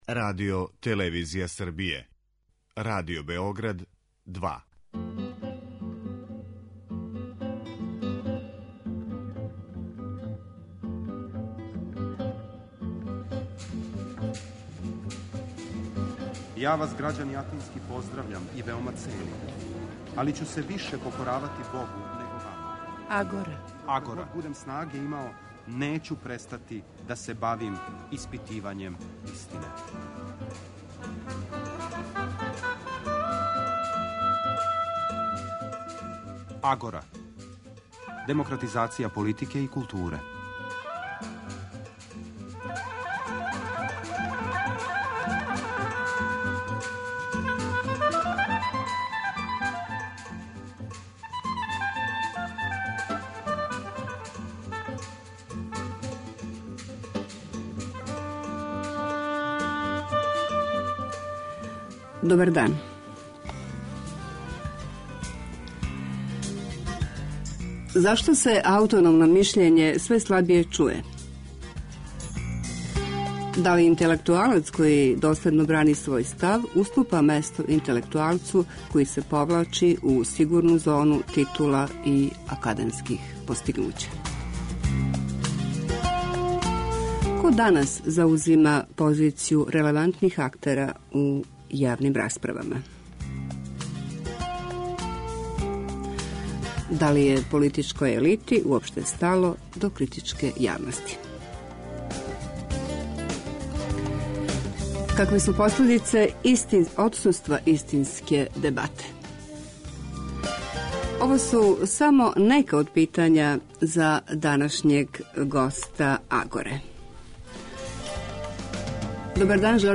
Радио-магазин